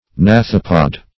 Search Result for " gnathopod" : The Collaborative International Dictionary of English v.0.48: Gnathopod \Gnath"o*pod\, n. [Gr. gna`qos the jaw + -pod.]